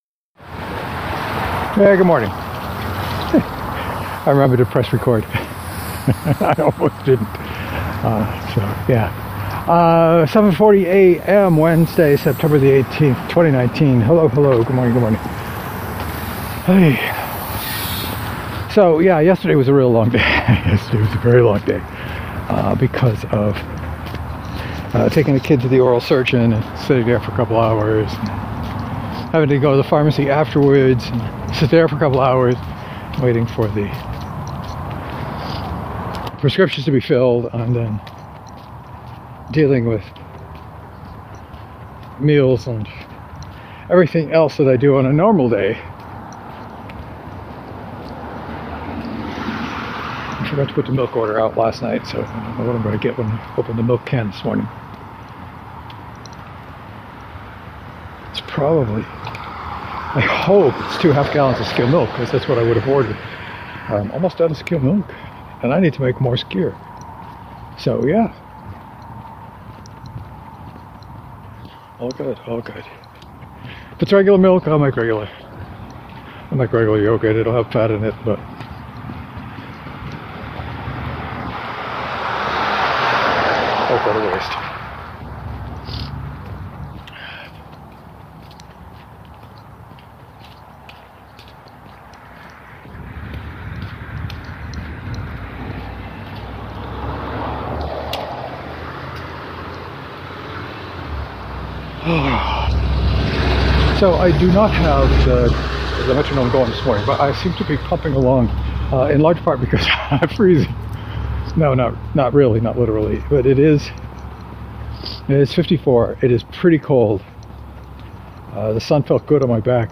I was pumping along this morning and motor mouthing most of the way.